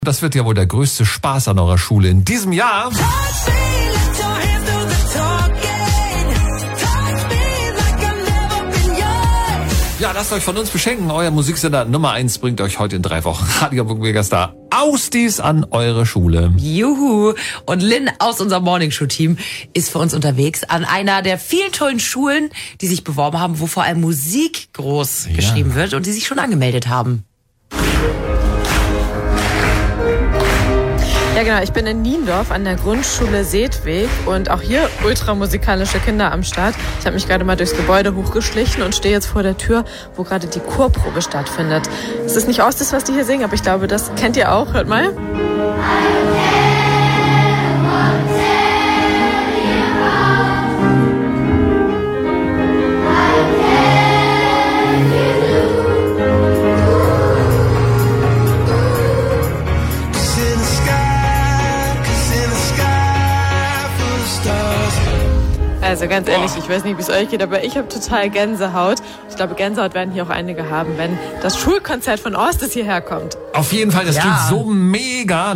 Radio Hamburg besuchte unsere Schule deshalb am 5. Februar und hat einen Mitschnitt vom Chor